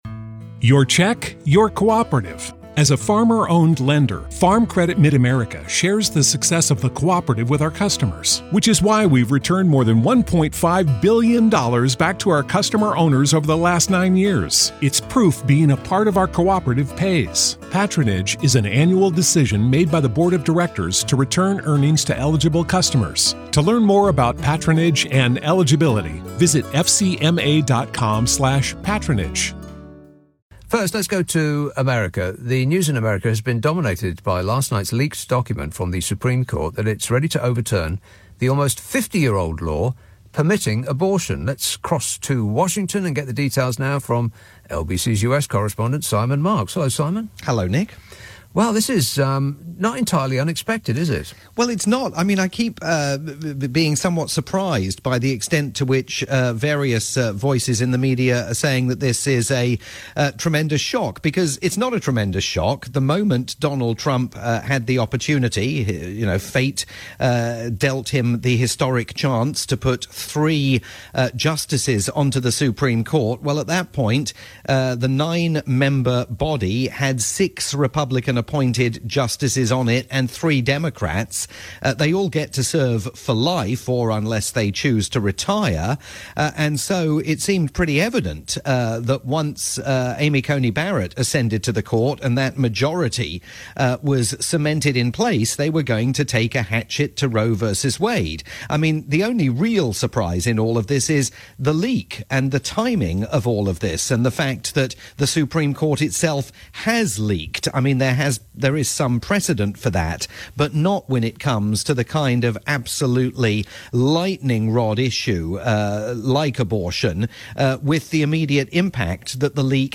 live chat